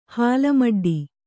♪ hāla maḍḍi